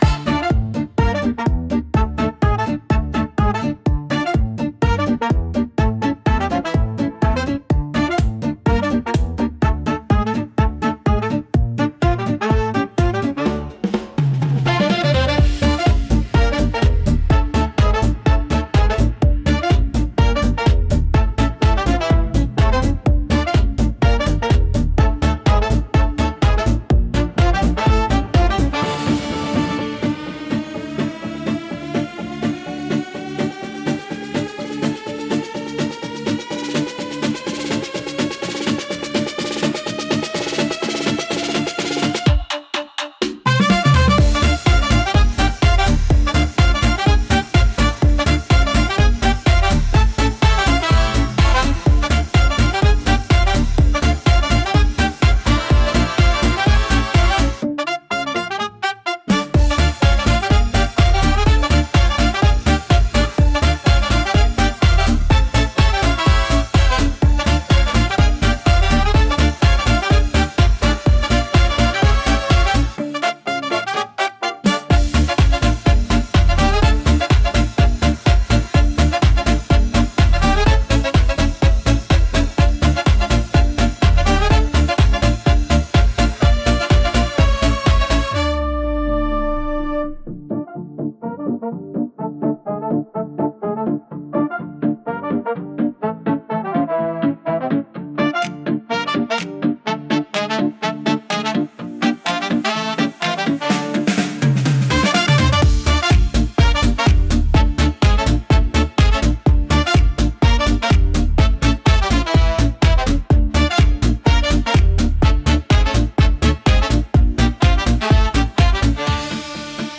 Instrumental / 歌なし
強めのブラスと勢いのあるドラムが生み出す、軽快でノリのいいエレクトロスウィング！